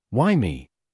Play, download and share siri – why me original sound button!!!!
siri-why-me.mp3